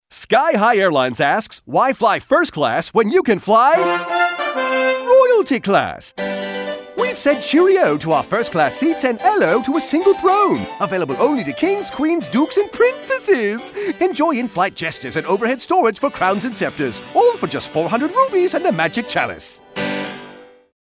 Radio Spots